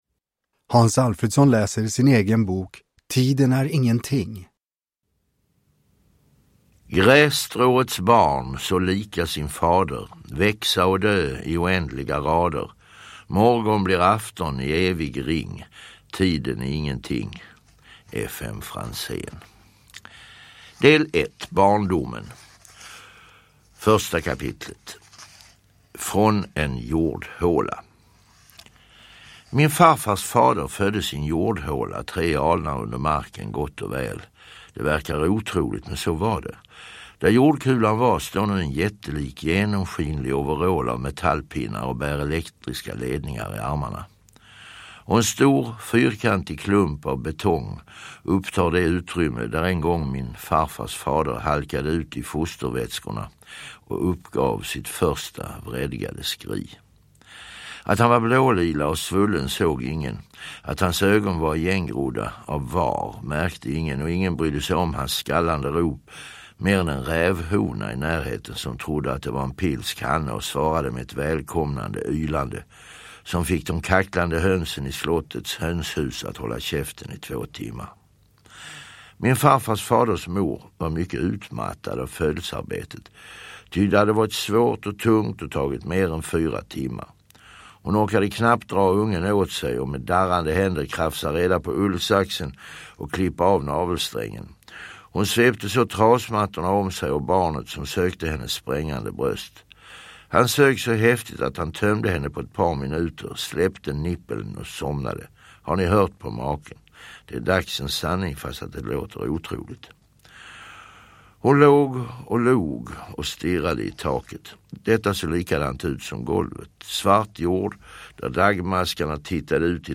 Uppläsare: Hans Alfredson
Ljudbok